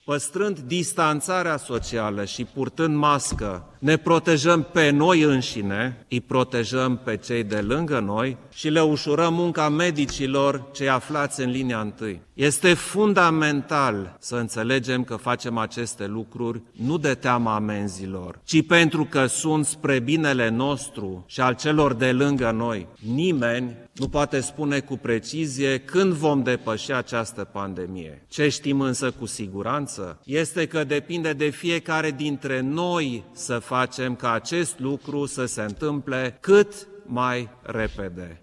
Preşedintele Klaus Iohannis a făcut apel la respectarea în continuare a regulilor instituite de Comitetul Naţional pentru Situaţii Speciale de Urgenţă: